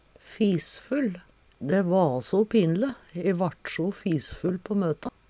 fisfull - Numedalsmål (en-US)